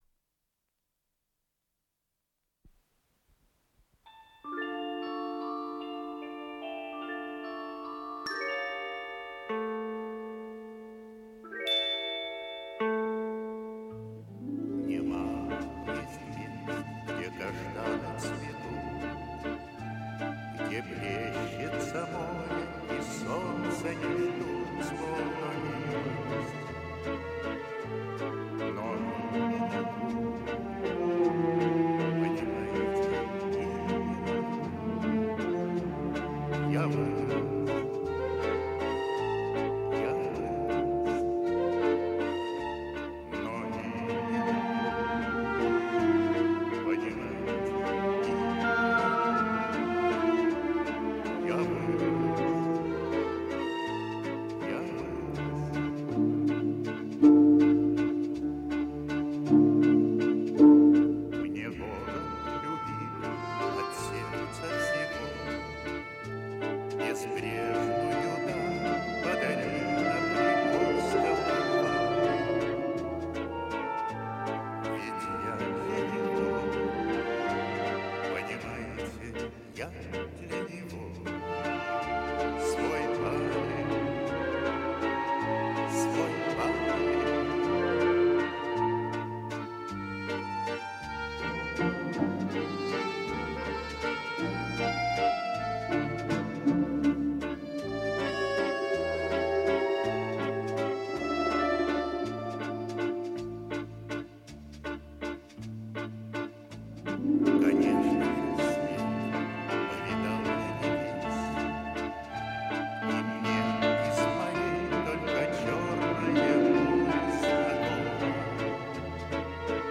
Стерео дубль.